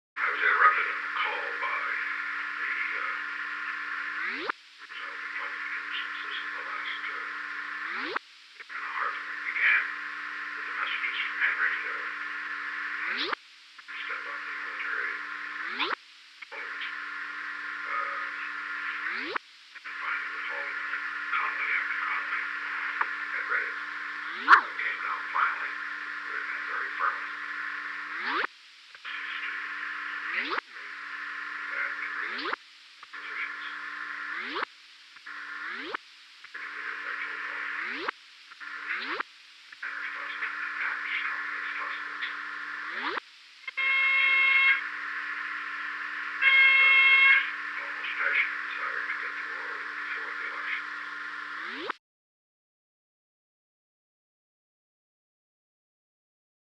Location: Camp David Hard Wire
The President dictated a memorandum.